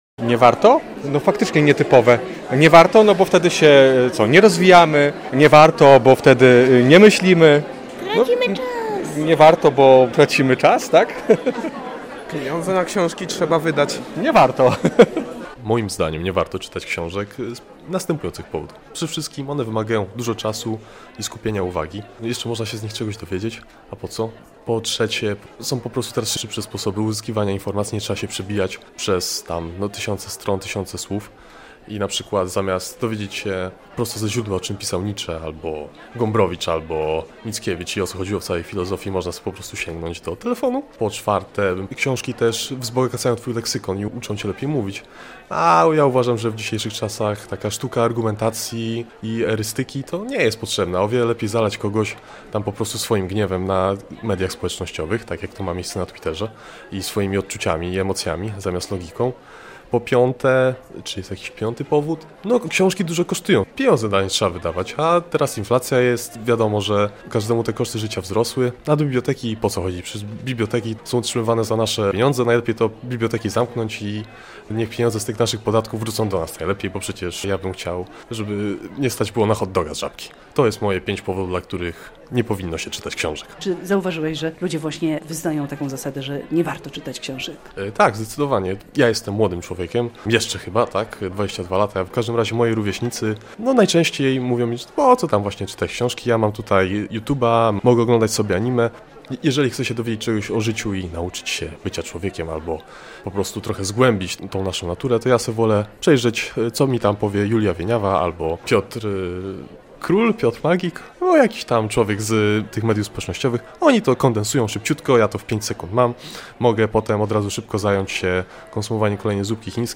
Dlaczego nie warto czytać książek? - relacja